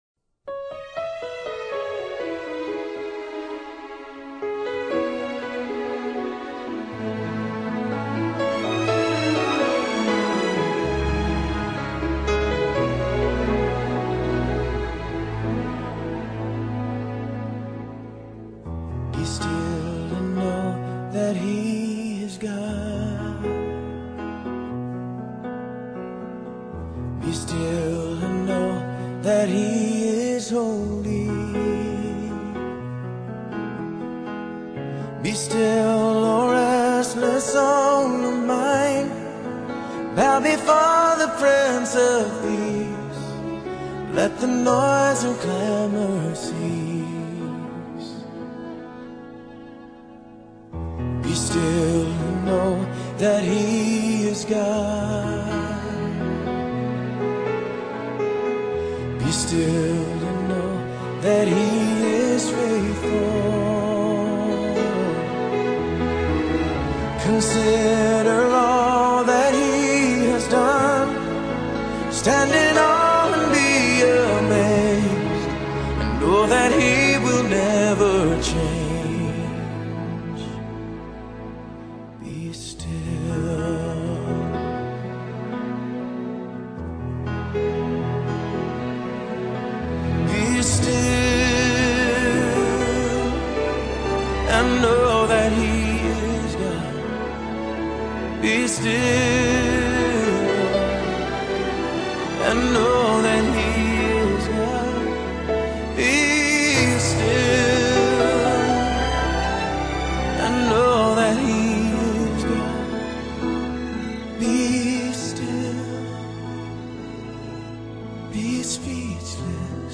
CHRISTIAN SONG